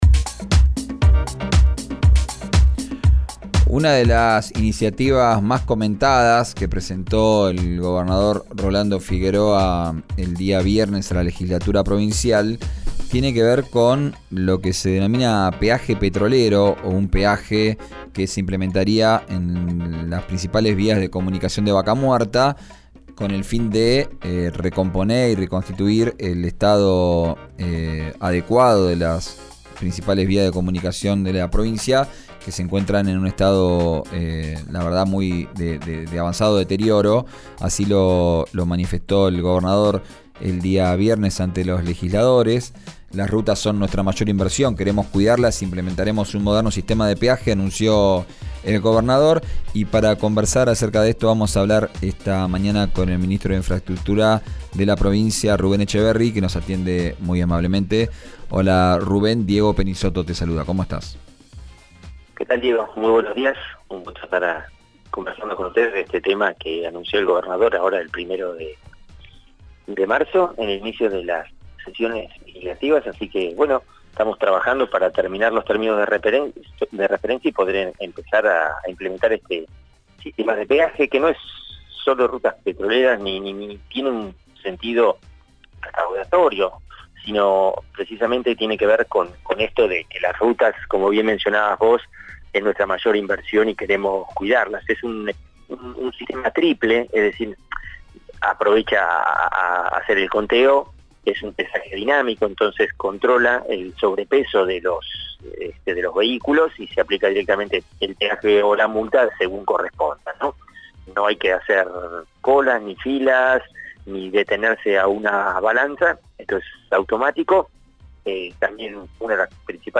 Escuchá al ministro de Infraestructura, Rubén Etcheverry, en RÍO NEGRO RADIO
En una entrevista con «Vos al Aire», por RÍO NEGRO RADIO, Etcheverry aclaró que el objetivo del cobro de peaje no es recaudatorio, sino de mantenimiento de las rutas actuales y también con el objetivo de poder pavimentar las del interior, bajo el sistema de regionalización que aplicó la actual gestión.